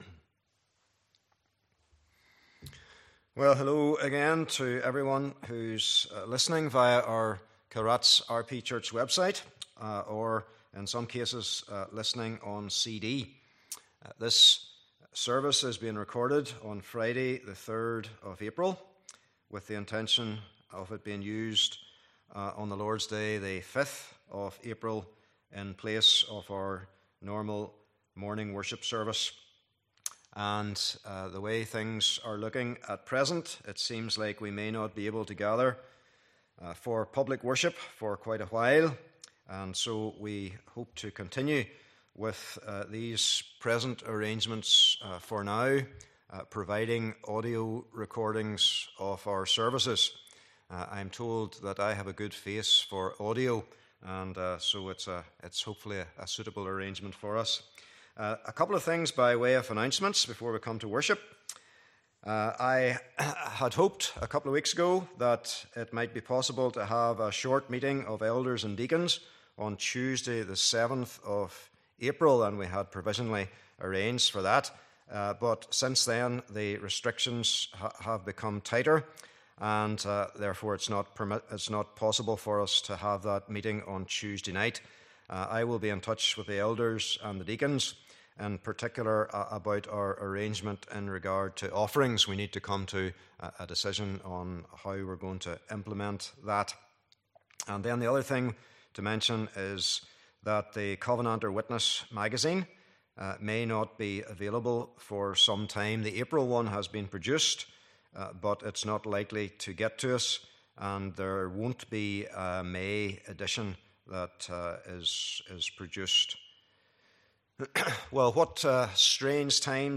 Passage: Exodus : 20 : 8 - 11 Service Type: Morning Service